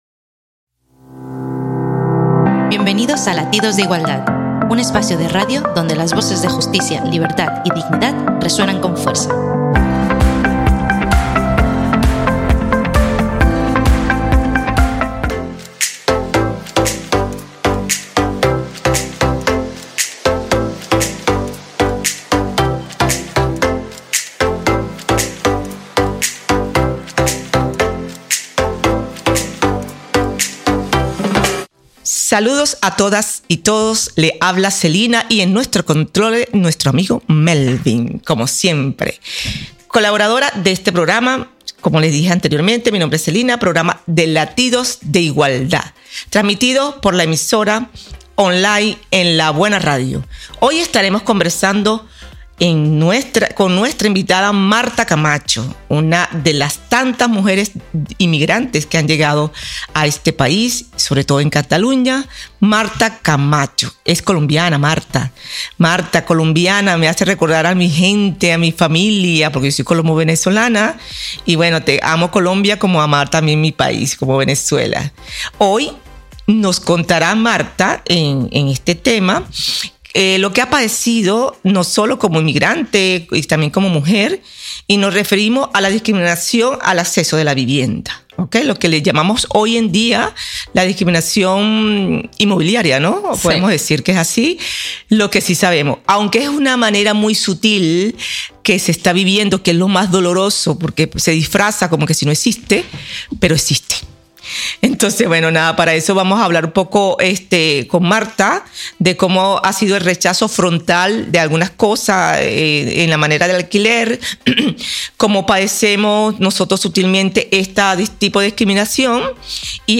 Entrevista racisme inmobiliari a Girona.